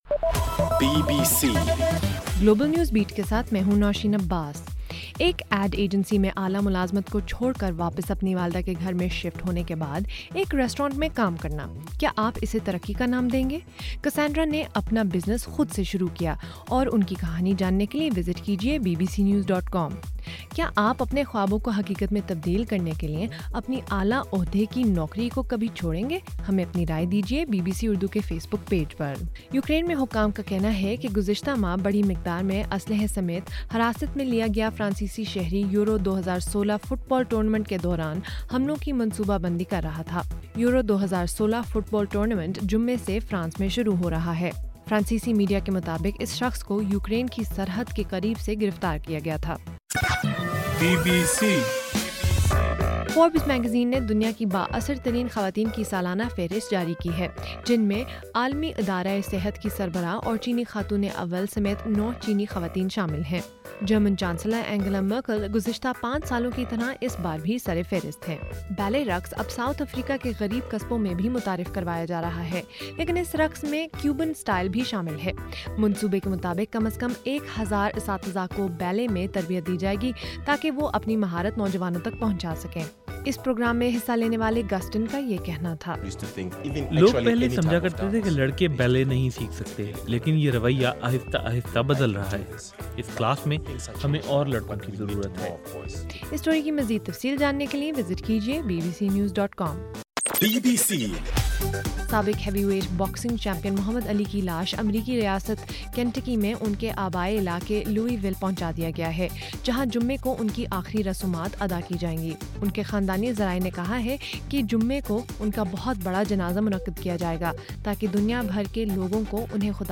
بُلیٹن